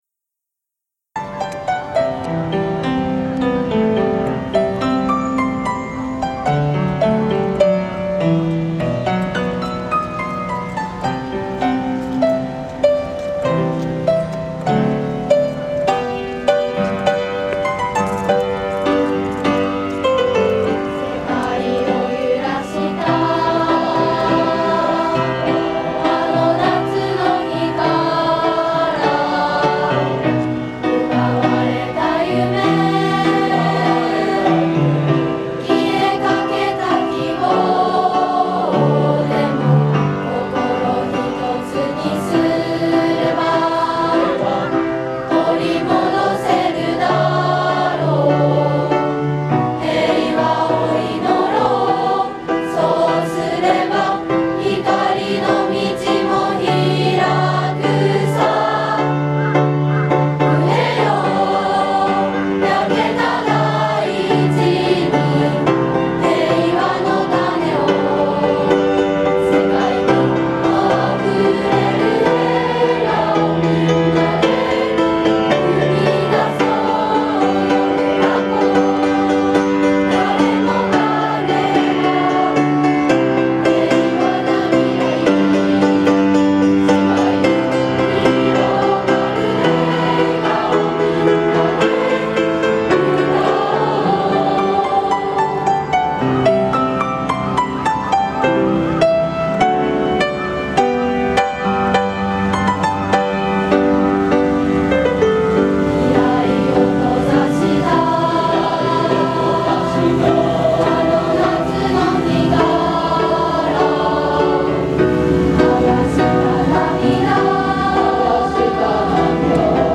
合唱曲「平和の種」
平和の種(音源、二部合唱) [その他のファイル／3.18MB]